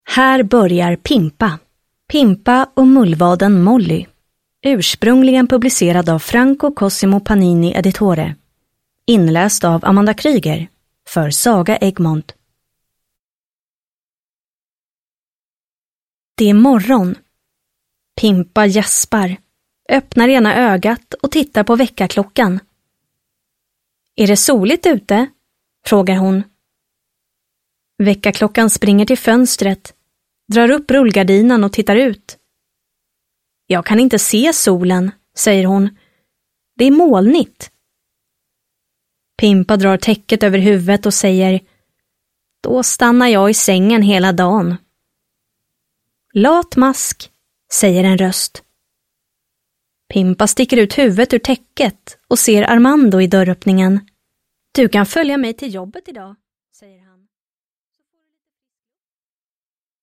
Pimpa - Pimpa och mullvaden Molly (ljudbok) av Altan